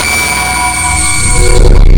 sonarPingWaterCloseShuttle1.ogg